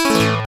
BrailleOff.wav